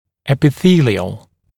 [ˌepɪ’θiːlɪəl][ˌэпи’си:лиэл]эпителиальный